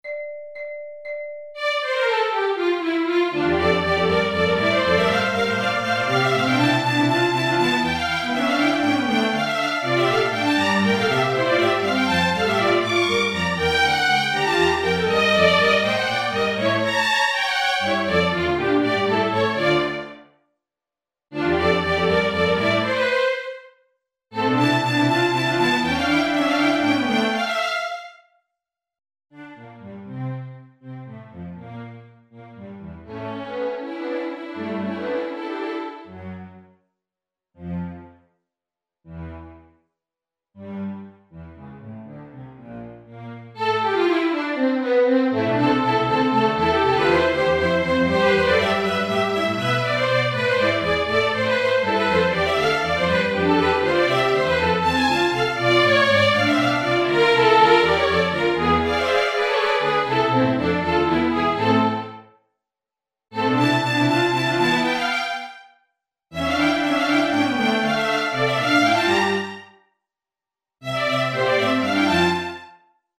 Si tratta di basi orchestrali, cameristiche e pianistiche.
BASI ORCHESTRALI